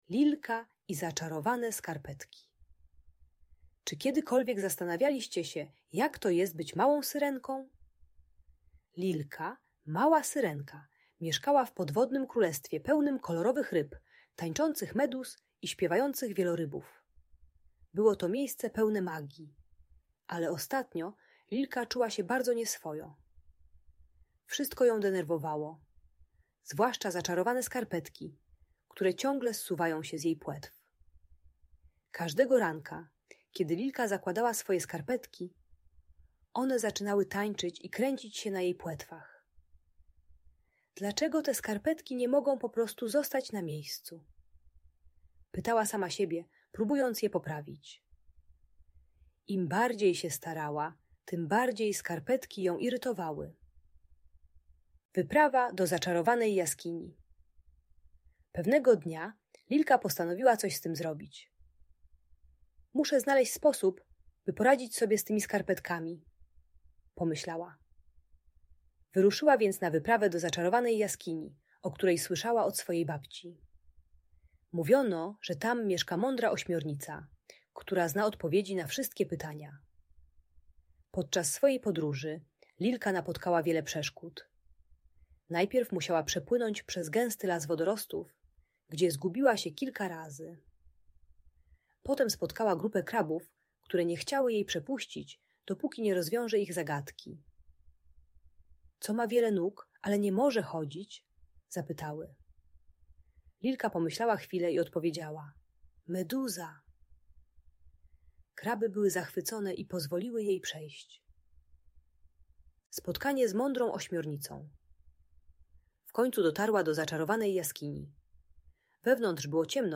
Historia Lilki i Zaczarowanych Skarpetek - Bunt i wybuchy złości | Audiobajka